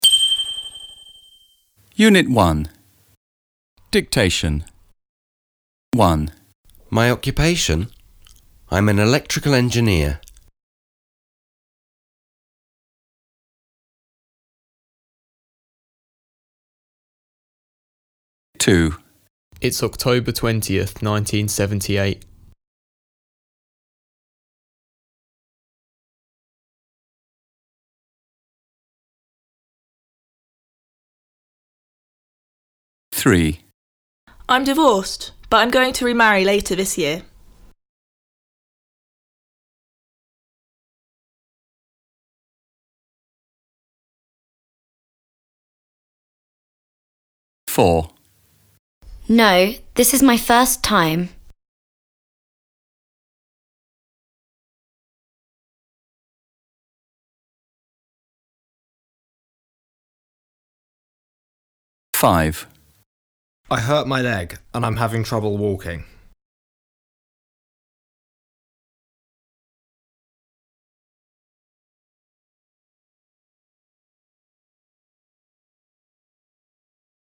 BMB_3Ed_Unit 1 Dictation.mp3